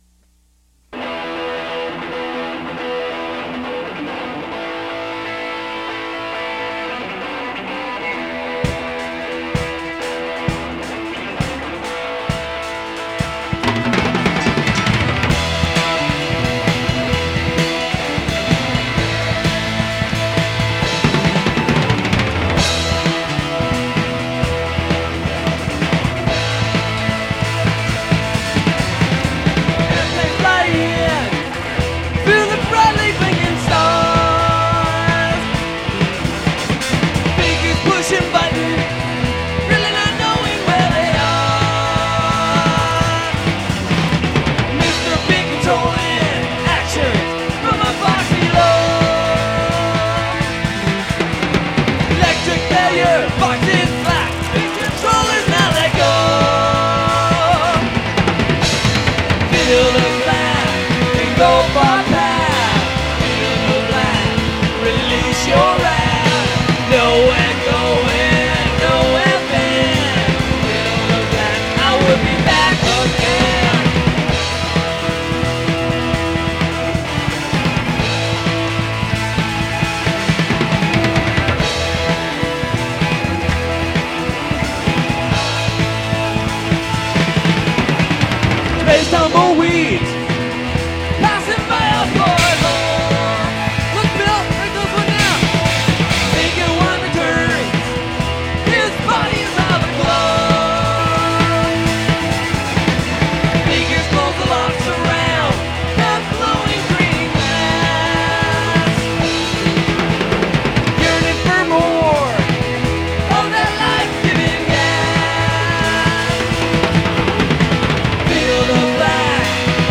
Oct ’92 – Basement Flood FoB
No intro, oral or otherwise. Guitar breaks are cleaner, there are more drum fills